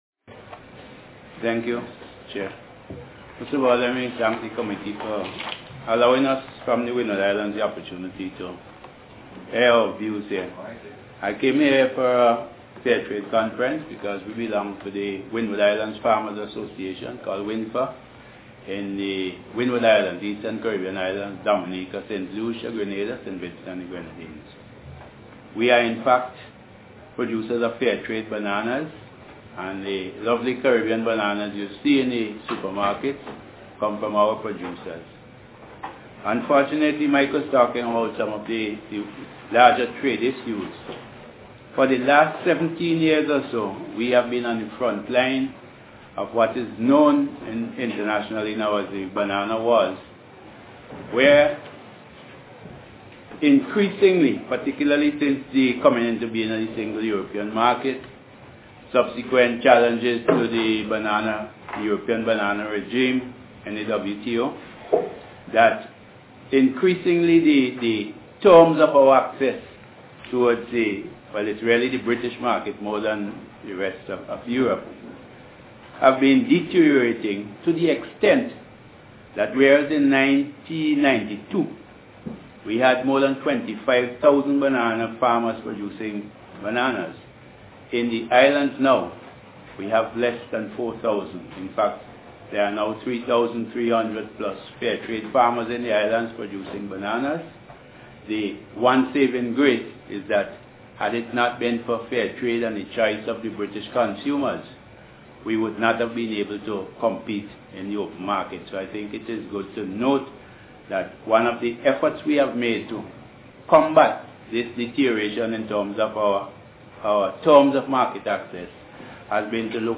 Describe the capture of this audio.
APPG evidence session Global Food Security First Evidence Session - Global Food Security